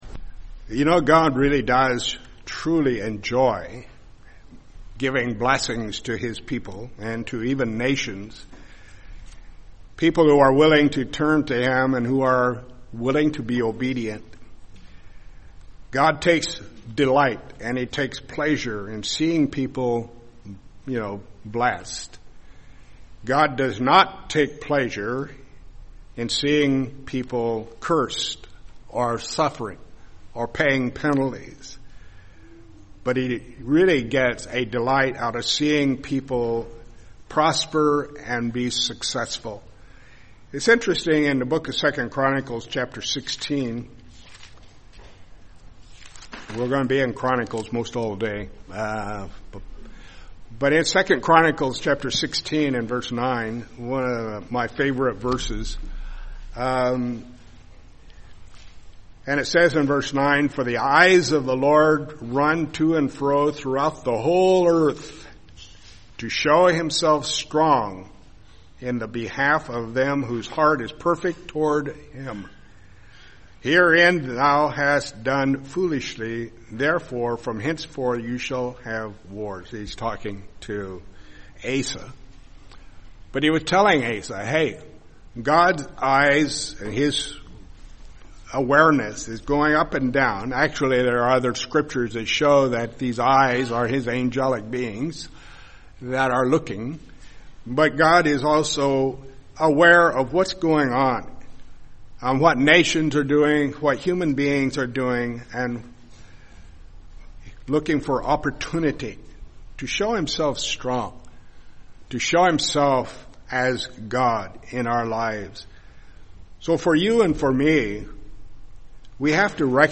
Sermons
Given in Lehigh Valley, PA